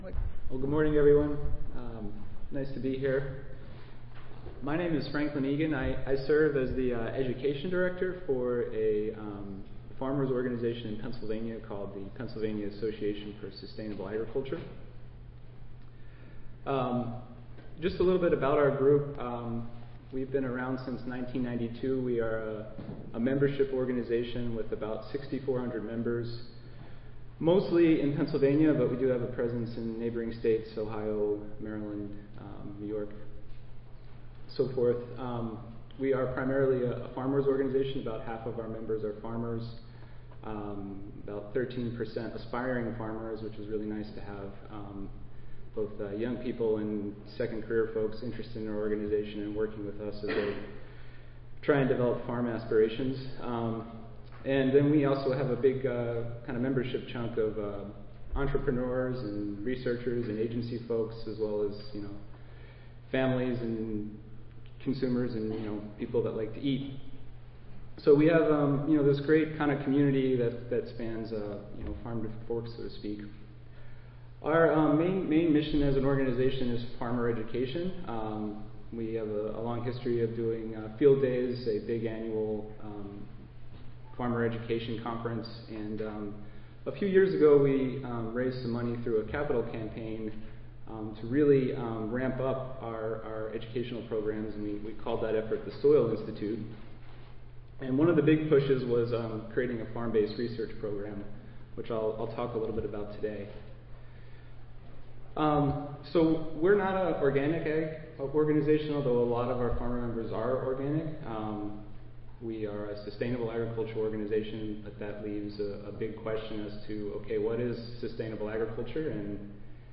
See more from this Division: Special Sessions See more from this Session: Special Session Symposium--Organic Agriculture Soil Health Research